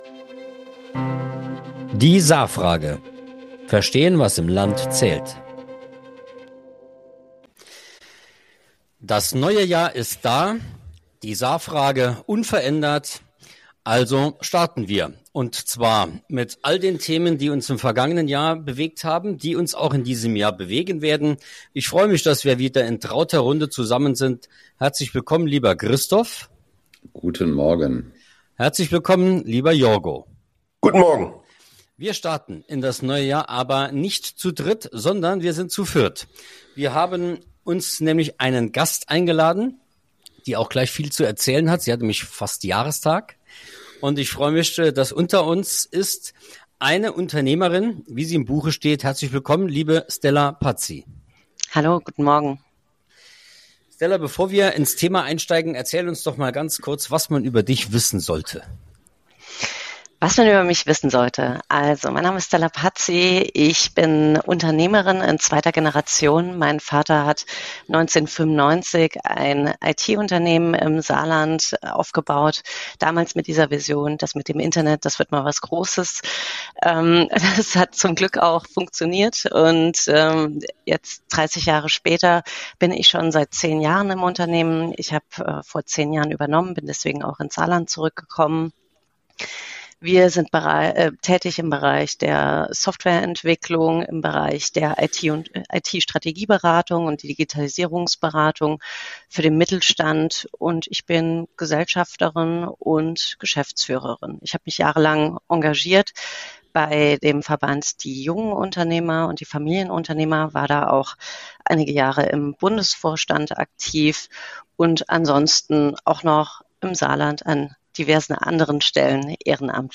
Ein Gespräch über Bürokratie und Brüche, über Wasserstoff, Verwaltung, Lebensqualität – und über die Verantwortung der politischen Mitte.